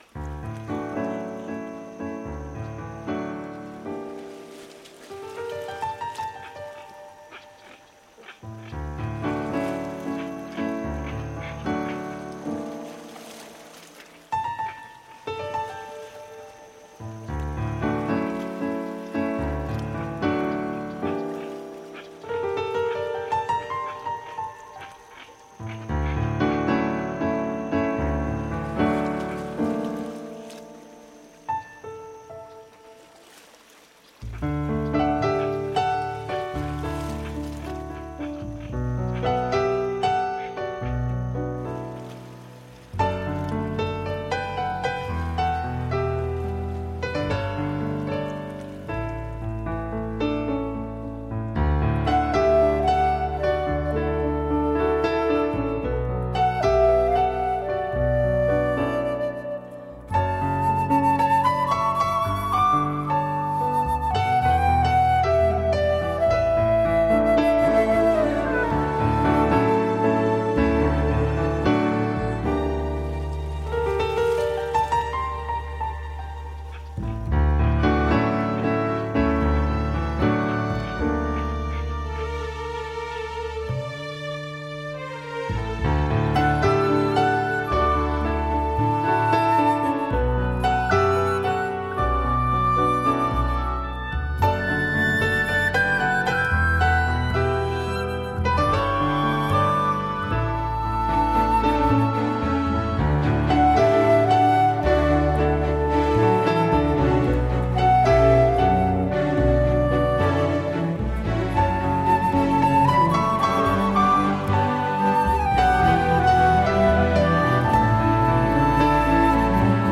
他们的音乐听起来更像流行乐。
这张专辑风格多变，每首曲目有很有节奏。